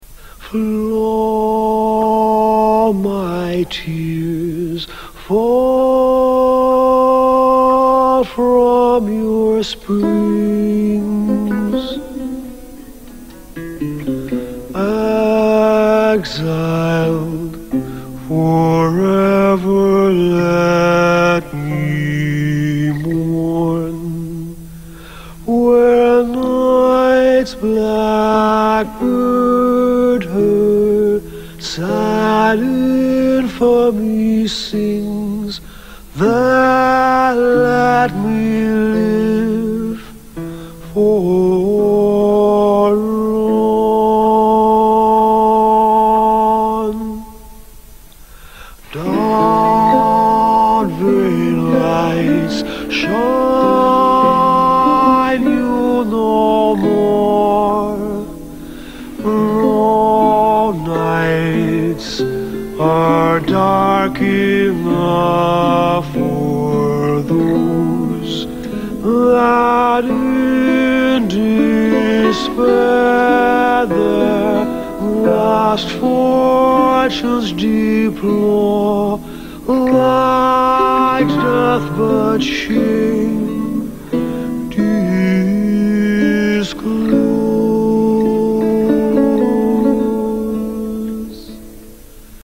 Monody